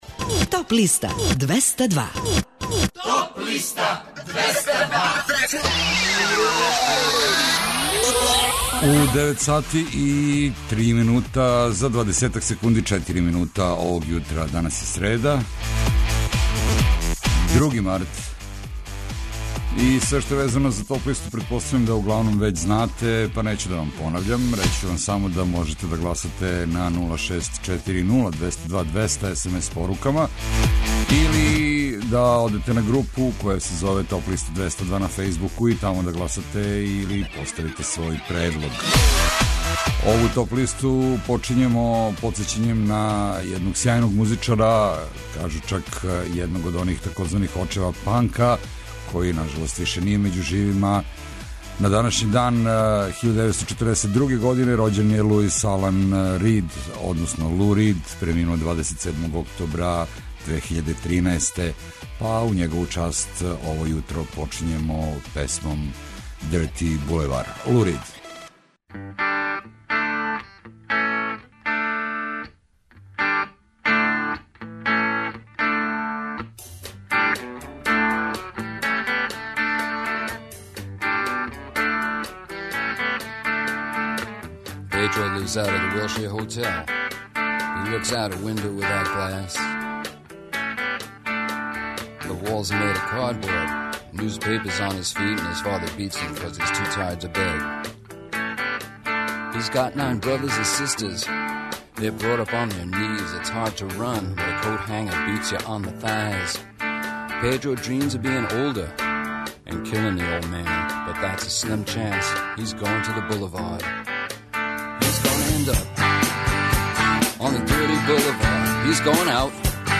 Emitovaćemo pesme sa podlista lektire, obrada, domaćeg i stranog roka, filmske i instrumentalne muzike, popa, etno muzike, bluza i džeza, kao i klasične muzike.